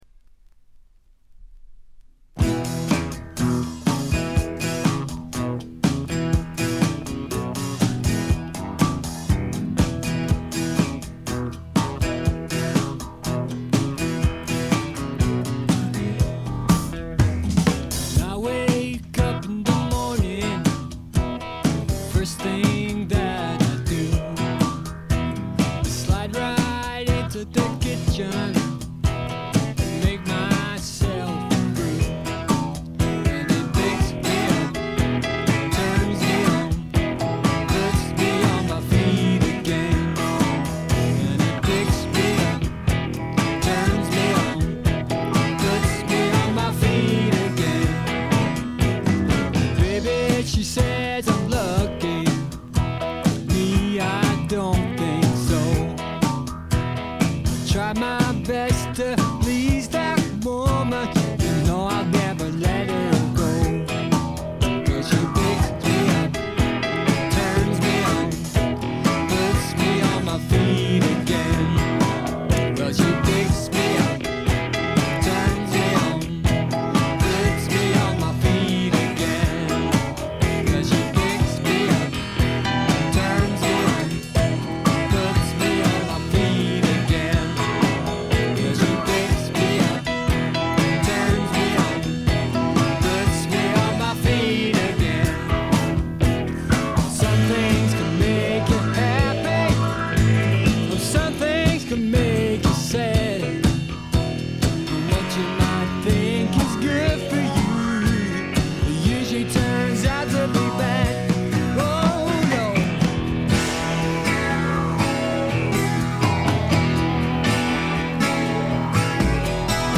部分試聴ですが、微細なチリプチ程度。
典型的かつ最良の英国製スワンプ・ロックを聴かせてくれます。
英国シンガーソングライター／スワンプの基本中の基本！
試聴曲は現品からの取り込み音源です。